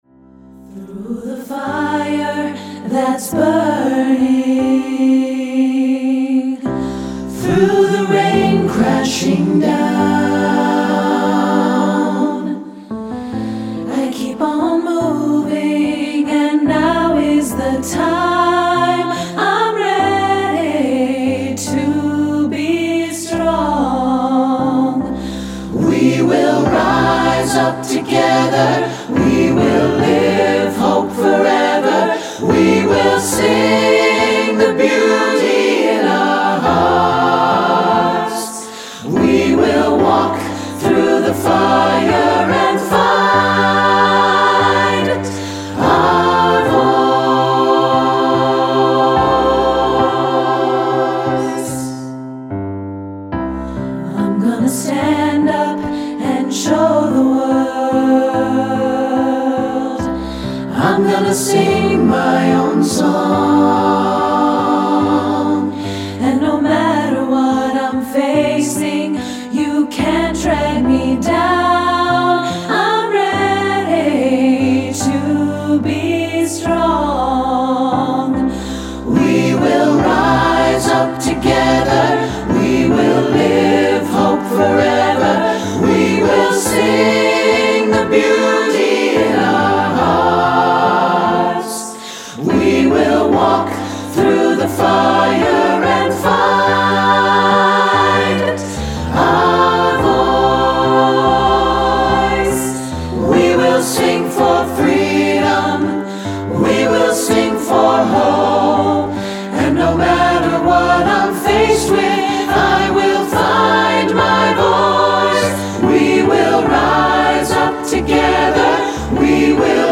Choral Concert/General Graduation/Inspirational
SATB